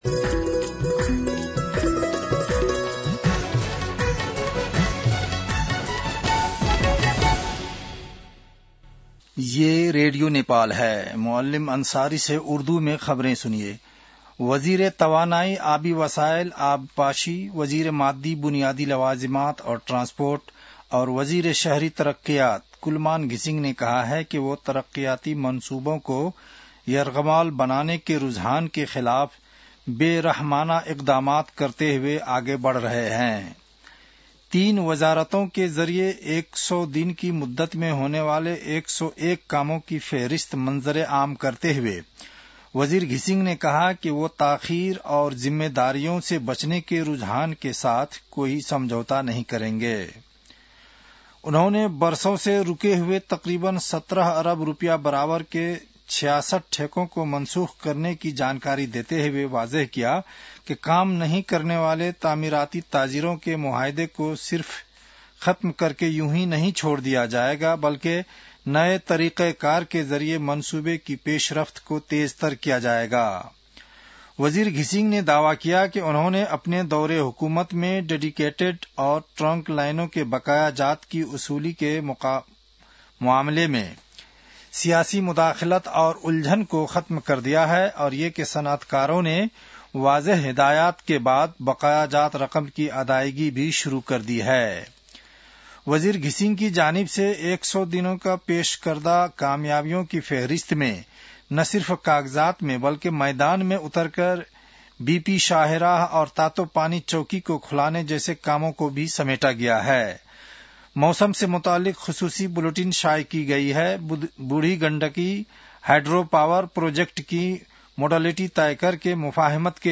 An online outlet of Nepal's national radio broadcaster
उर्दु भाषामा समाचार : ९ पुष , २०८२